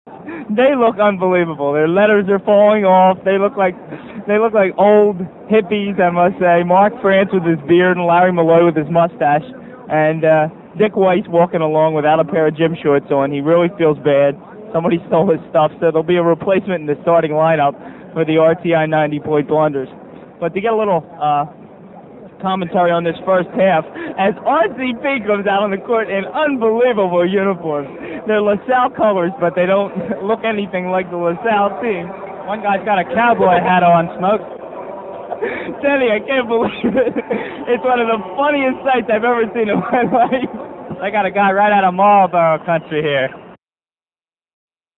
The game took place in Philadelphia's famous Palestra, at half-time of a real game between Temple and Penn State.
For that, we have the actual play-by-play audio.